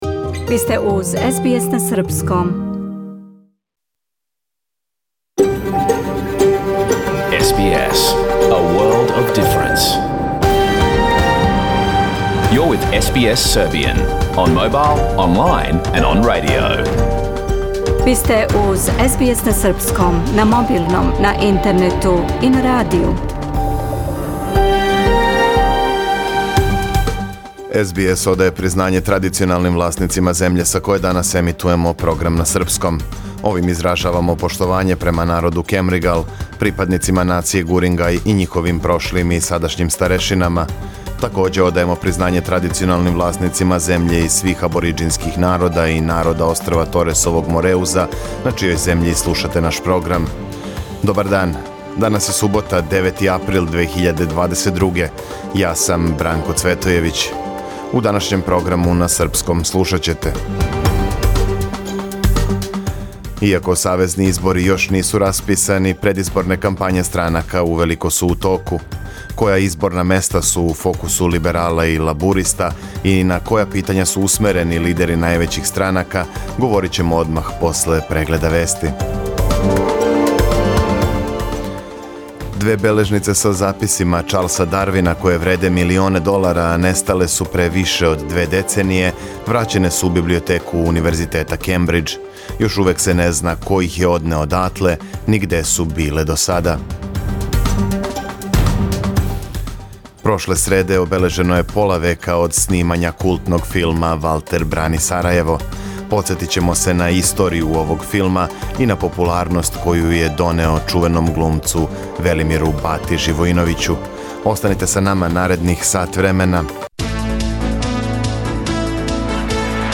Програм емитован уживо 9. априла 2022. године
Ако сте пропустили нашу емисију, сада можете да је слушате у целини као подкаст, без реклама.